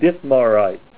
Help on Name Pronunciation: Name Pronunciation: Dittmarite + Pronunciation